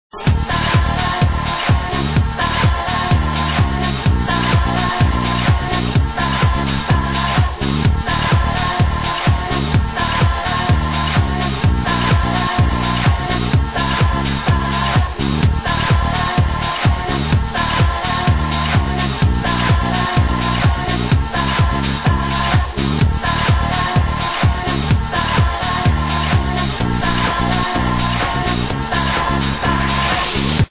Please help to ID this house tunes!!!
damn, I hope this one gets id'd, its really funky !